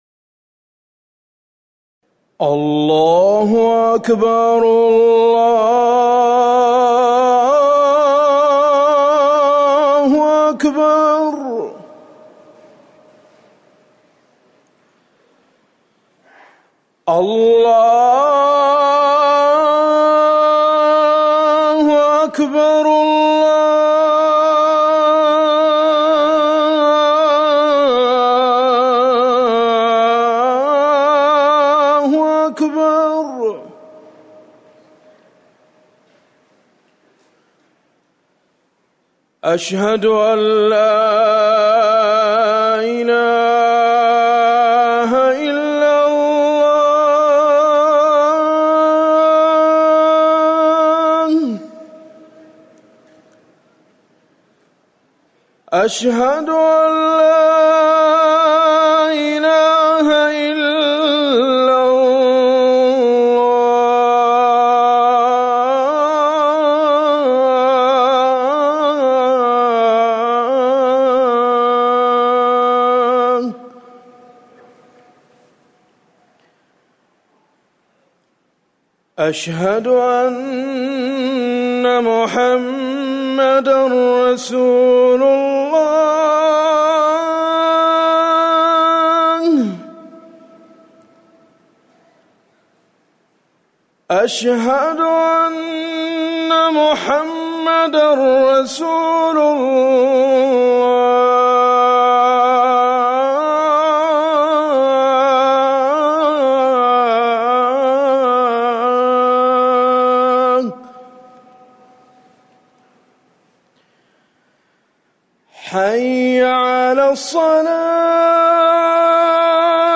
أذان العصر
تاريخ النشر ١٩ محرم ١٤٤١ هـ المكان: المسجد النبوي الشيخ